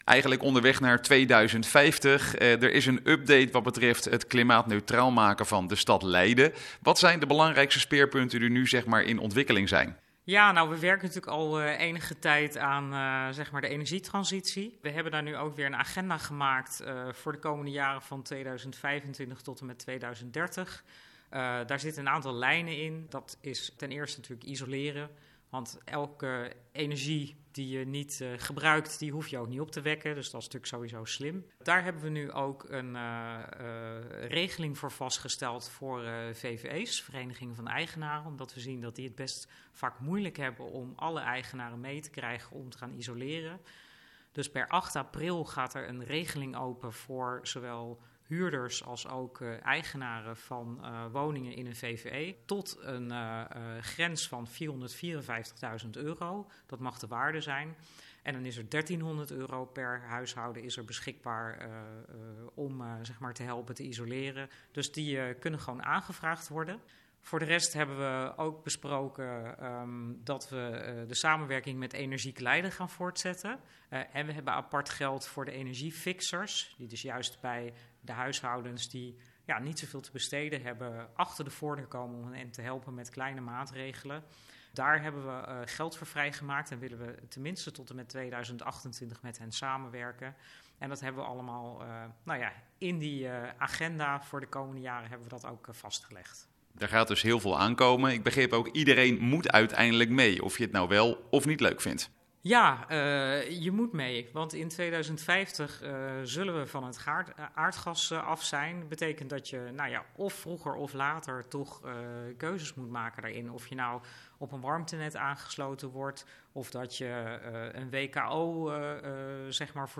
Wethouder Energie Yvonne van Delft in gesprek